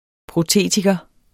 Udtale [ pʁoˈteˀtigʌ ]